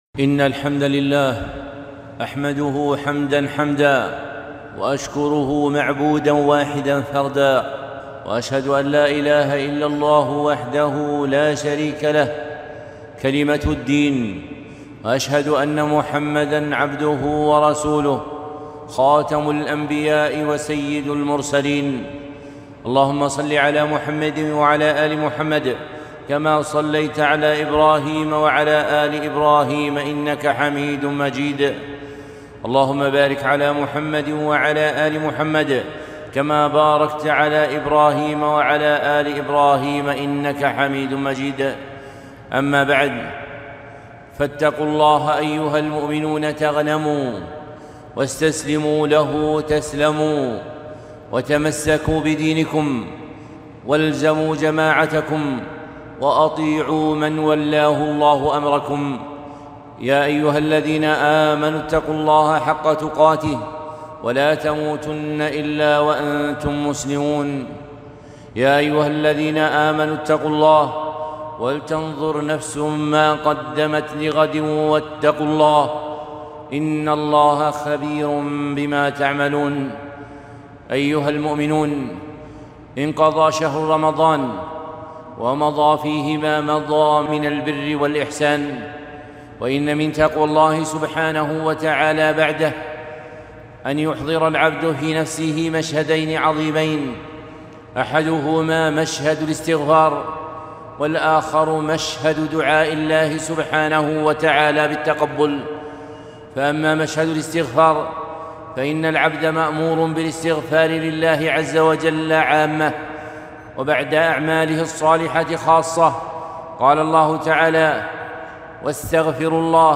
خطبة - مشاهد الإحسان بعد شهر رمضان